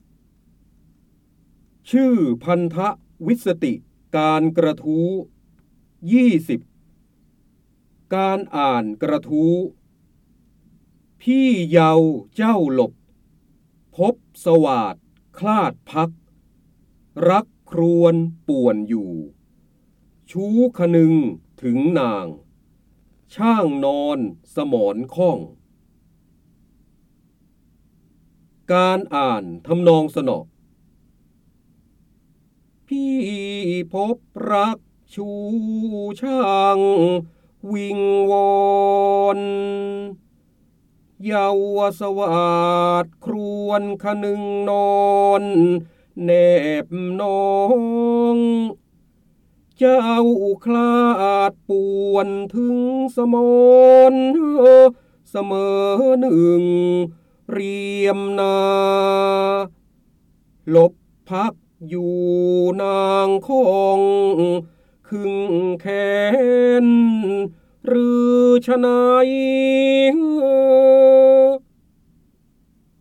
เสียงบรรยายจากหนังสือ จินดามณี (พระโหราธิบดี) ชื่อพันธวิสติการกระทู้ ๒o